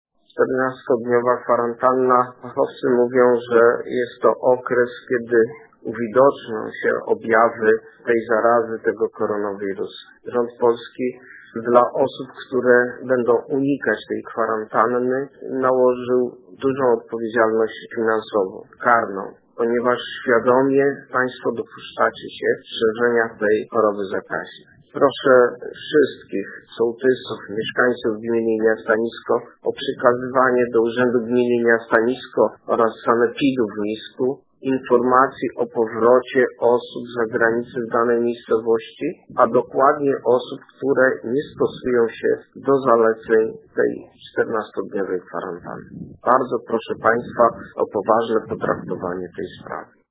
Nisko: Burmistrz Niska apeluje do mieszkańców o zgłaszanie przypadków łamania kwarantanny